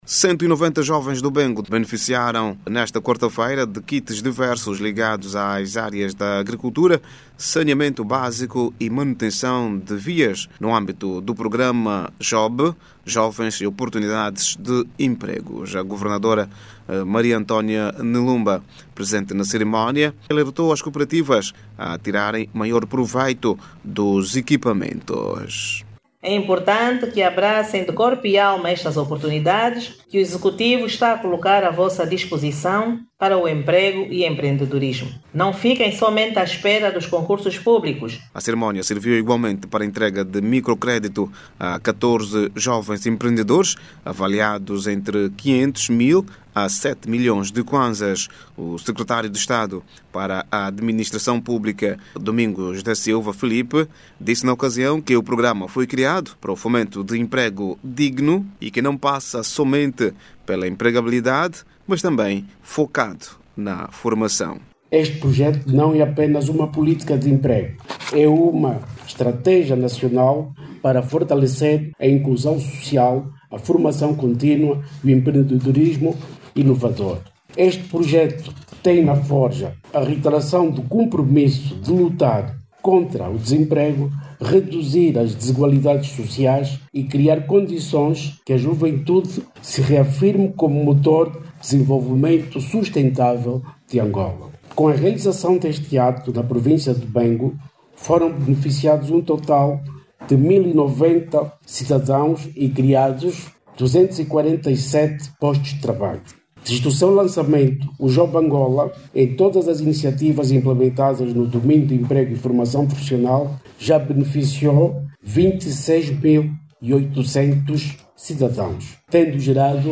O programa JOB Angola, chegou à Província Bengo e beneficia 190 jovens daquela província. Durante a cerimonia de lançamento, que aconteceu nesta quarta-feira, foram entregues kits para a promoção do auto-emprego e microcréditos. Clique no áudio abaixo e ouça a reportagem